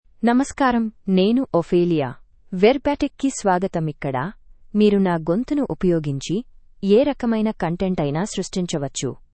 Ophelia — Female Telugu AI voice
Ophelia is a female AI voice for Telugu (India).
Voice sample
Listen to Ophelia's female Telugu voice.
Ophelia delivers clear pronunciation with authentic India Telugu intonation, making your content sound professionally produced.